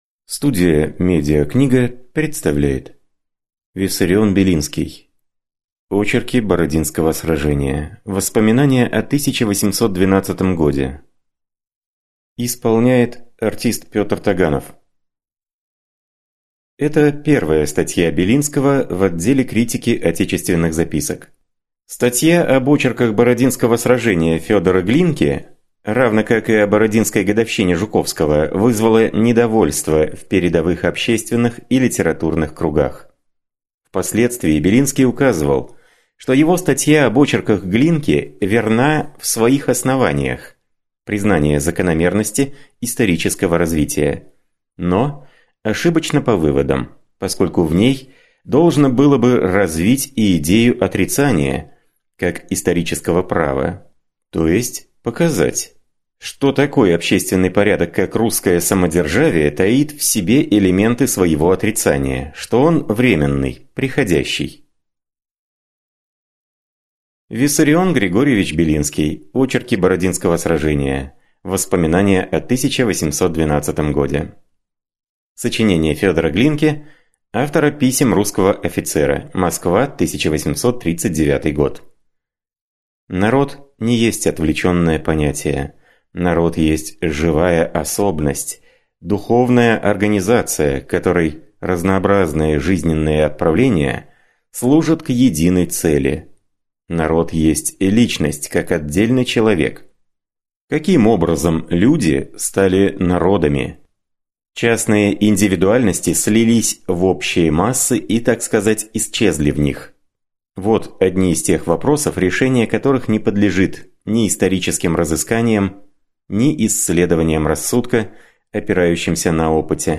Аудиокнига Очерки бородинского сражения (Воспоминания о 1812 годе) | Библиотека аудиокниг